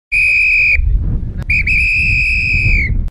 Hacé click y escuchá los sonidos mas carácterísticos de La Trochita
SILBATO.mp3